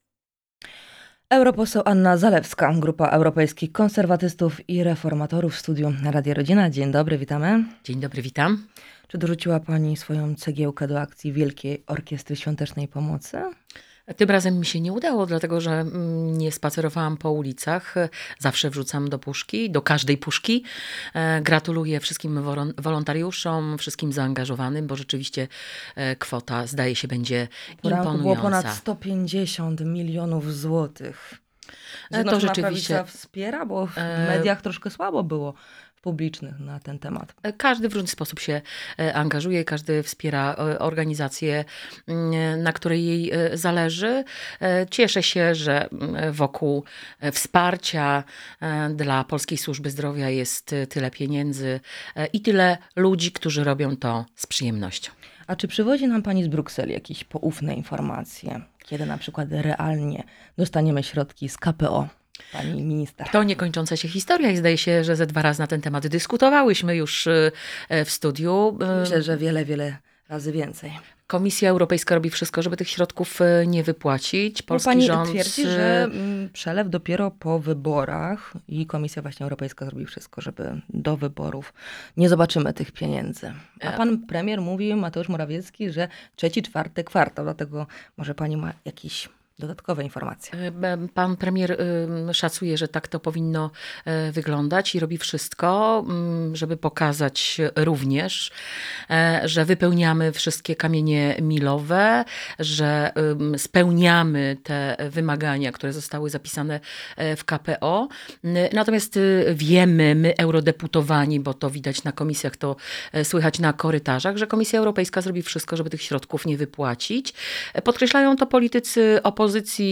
- Komisja Europejska zrobi wszystko, by nie wypłacić środków z KPO – mówiła Europoseł Anna Zalewska z grupy Europejskich Konserwatystów i Reformatorów w audycji "Poranny Gość" .